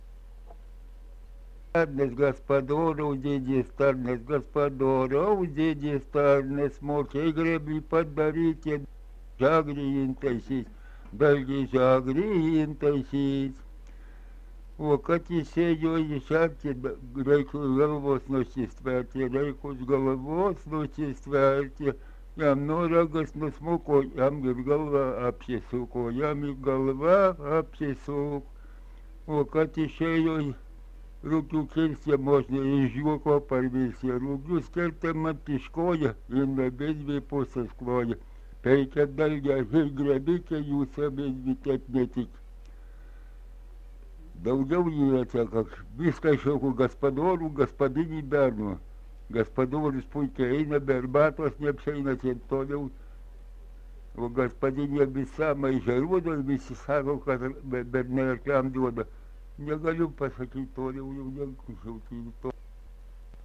Dalykas, tema daina
Erdvinė aprėptis Ausieniškės
Atlikimo pubūdis Mišrus
Instrumentas smuikas